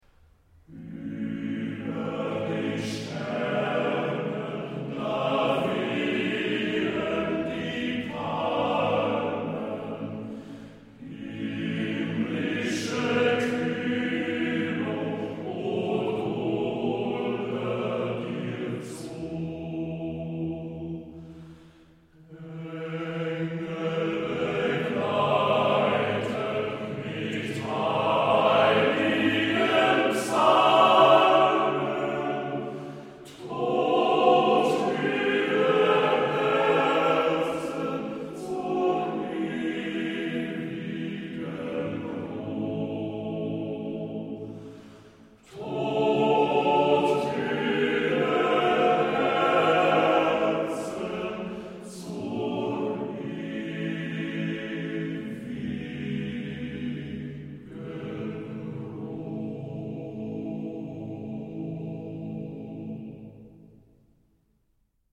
DER MÄNNERCHOR